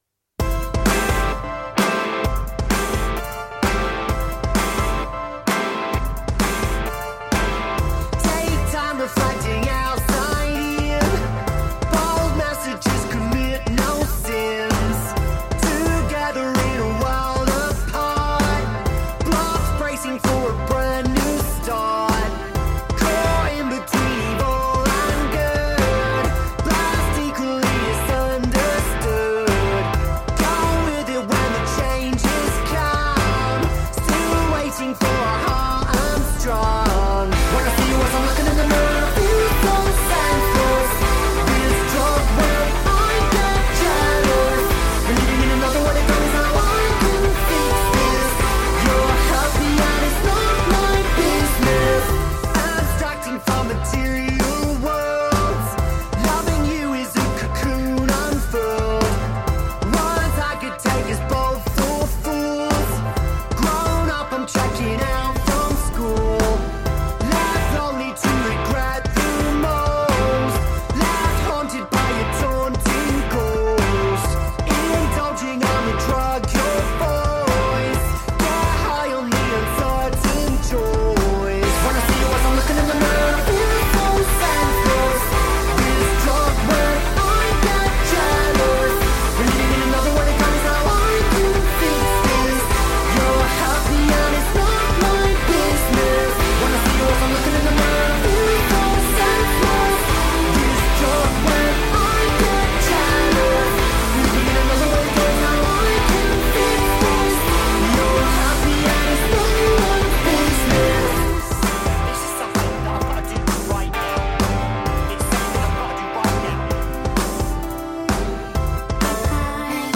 Intervista The Subways | Rocktrotter | 31-3-23 | Radio Città Aperta
intervista-the-subways-31-3-23.mp3